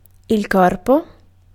Ääntäminen
IPA: /ˈkɔr.po/